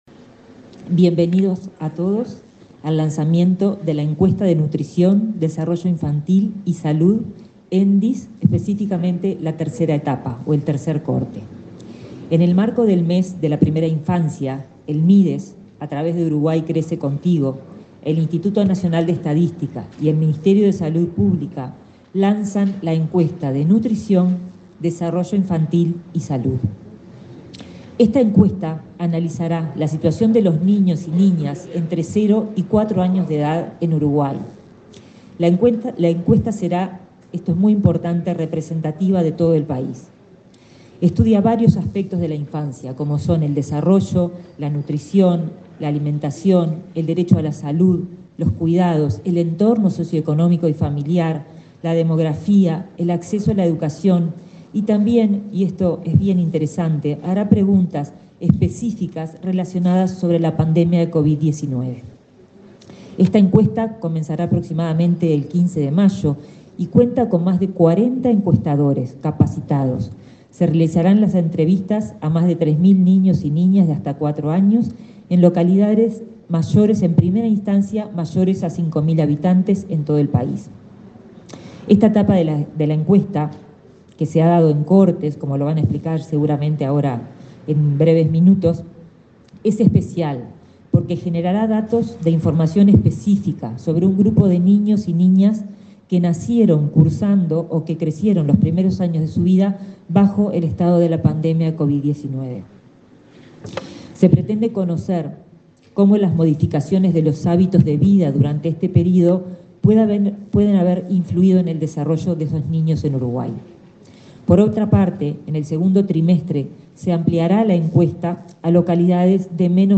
Palabras de autoridades en acto del MSP